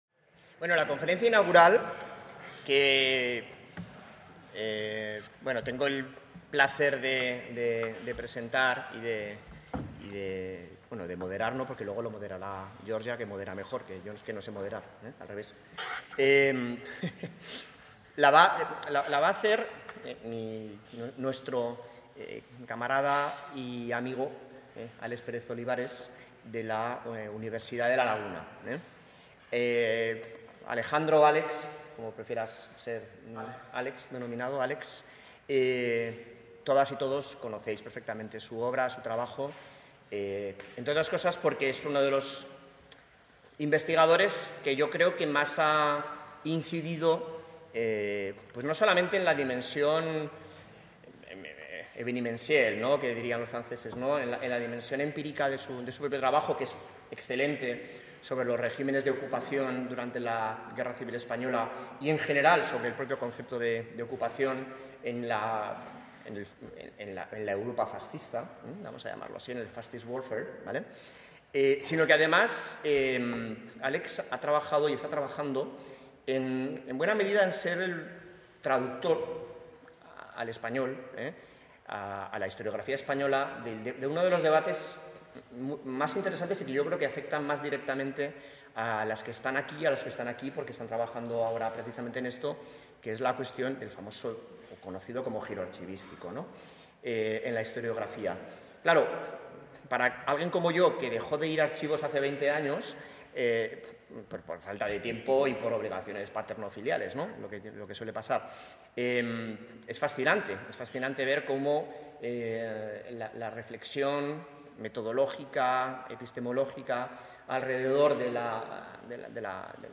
Conferencia inaugural: Repensar el poder de los archivos: ¿de "giro" a práctica historiográfica?